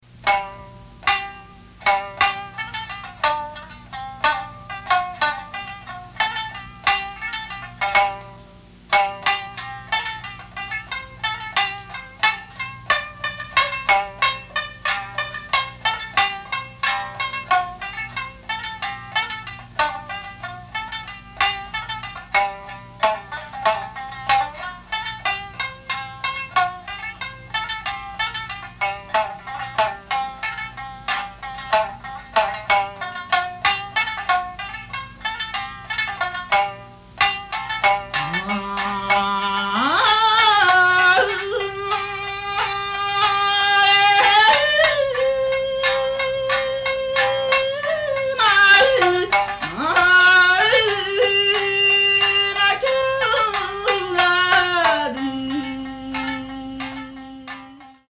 全16曲　昭和37年に収録したものです
唄・三線
囃子
百年に一人と言われた美声とドラマチックな歌いっぷりは当時のファンたちを一瞬にして虜（とりこ）とした。